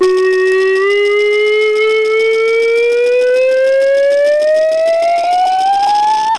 SlideSlow.wav